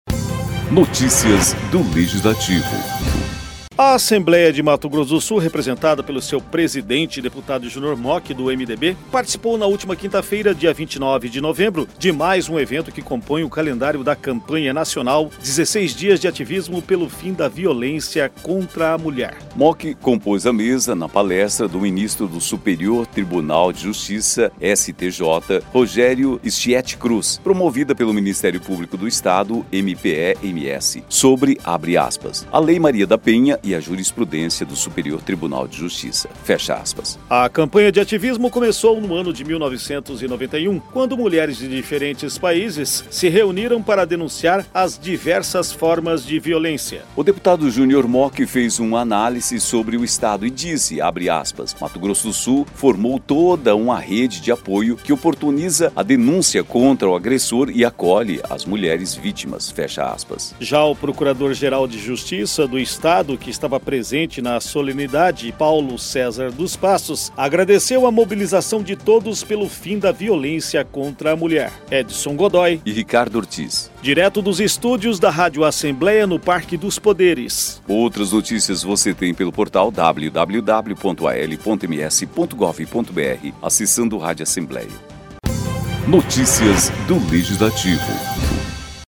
ALMS continua ativismo pelo fim da violência contra a mulher e participa de evento